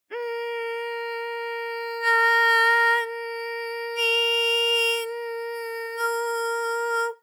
ALYS-DB-001-JPN - First Japanese UTAU vocal library of ALYS.